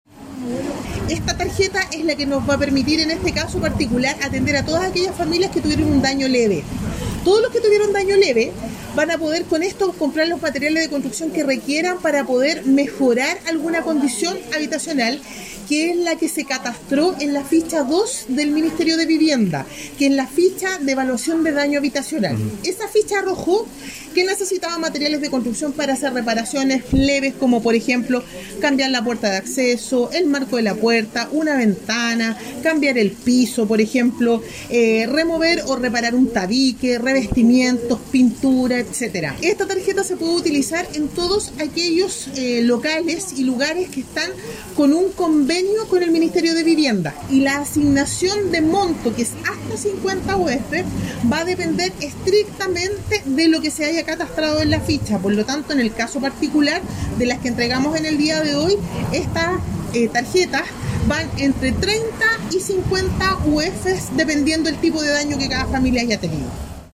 La entrega de las tarjetas fue concretada en la sede social de Majadillas por parte de la directora de SERVU Paula Oliva Aravena, acompañada por el alcalde Enrique Olivares Farías, la seremi de las Culturas Ángela Campos Hernández y concejales de la comuna.